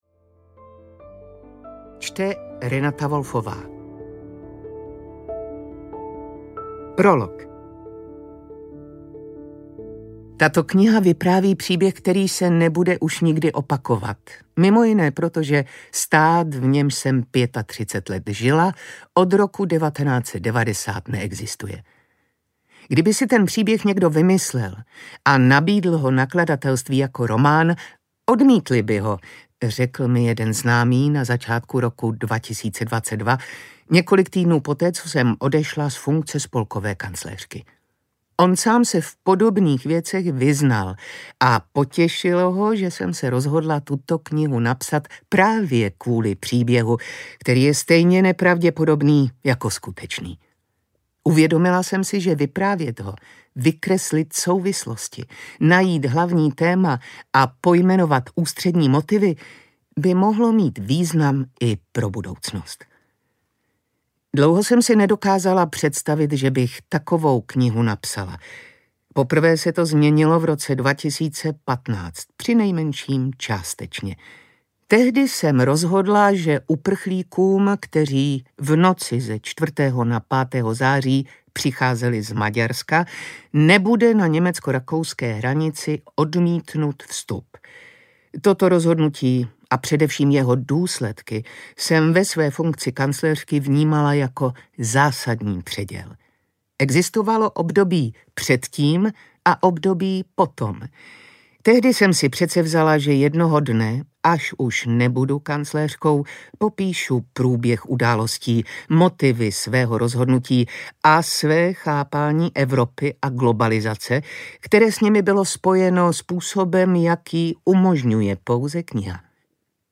Svoboda audiokniha
Ukázka z knihy
svoboda-audiokniha